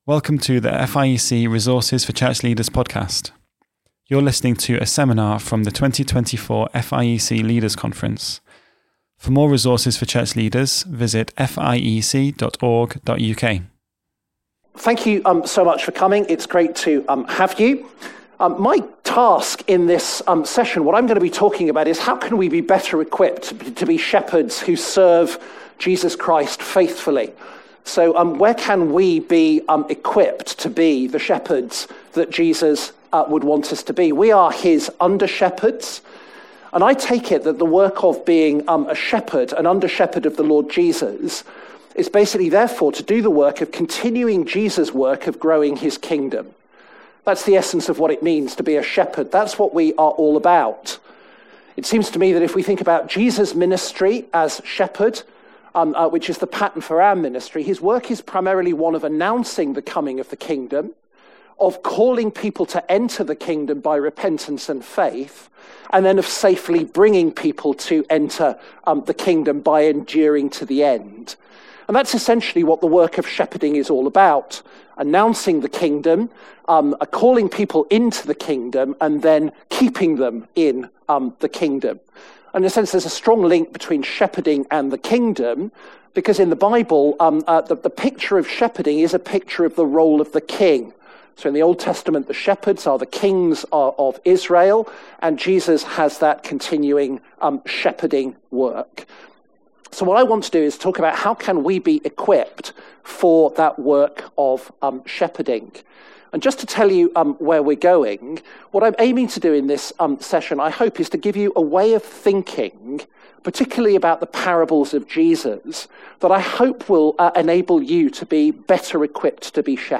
The parables of Jesus are kingdom stories which answer key questions about the kingdom of Christ. A seminar from the 2024 Leaders' Conference.